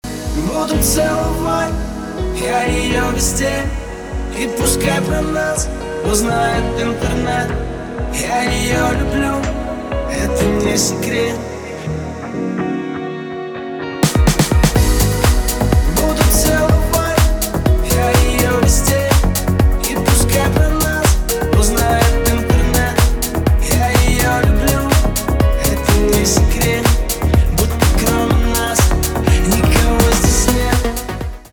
танцевальные
романтические , чувственные , кайфовые , пианино